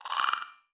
Game Notification.wav